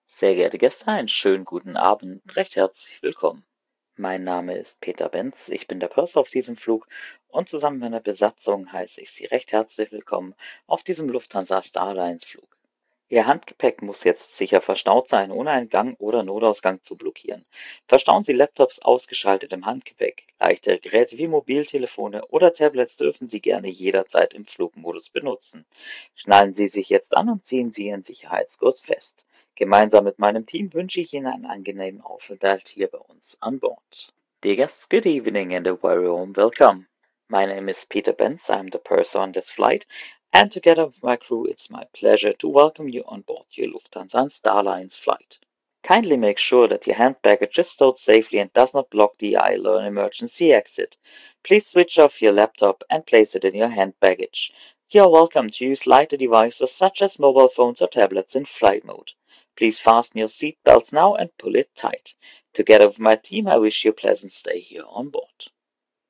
PreSafetyBriefing[Evening].ogg